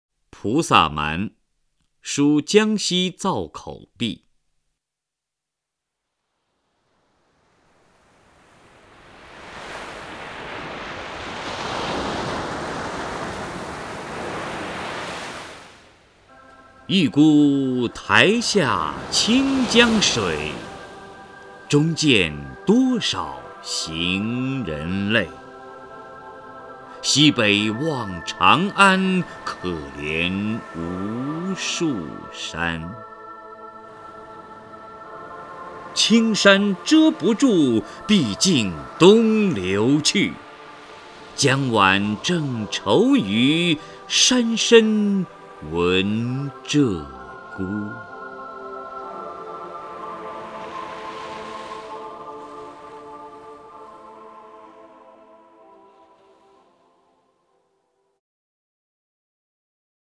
首页 视听 名家朗诵欣赏 王波
王波朗诵：《菩萨蛮·书江西造口壁》(（南宋）辛弃疾)